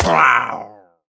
Minecraft Version Minecraft Version 1.21.5 Latest Release | Latest Snapshot 1.21.5 / assets / minecraft / sounds / mob / pillager / death1.ogg Compare With Compare With Latest Release | Latest Snapshot
death1.ogg